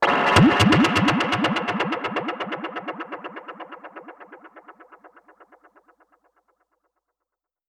Index of /musicradar/dub-percussion-samples/125bpm
DPFX_PercHit_B_125-03.wav